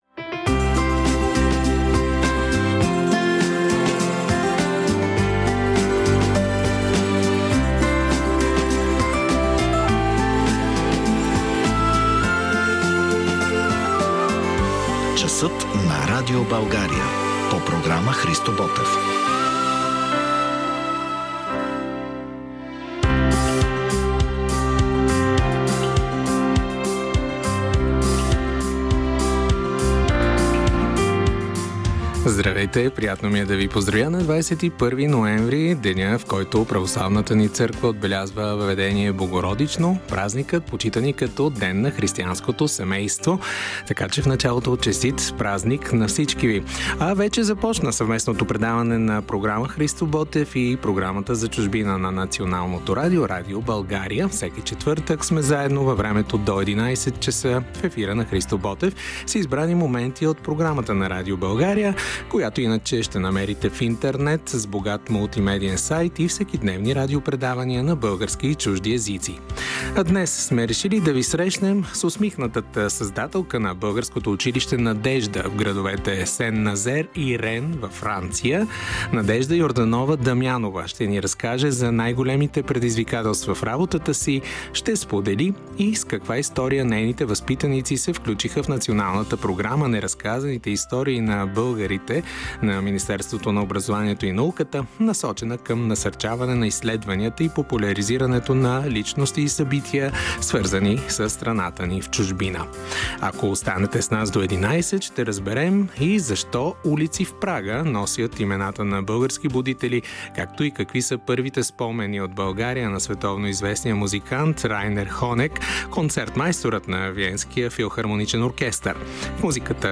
В брой 27 от Часът на Радио България, излъчен на 21 ноември 2024 – Деня на християнското семейство: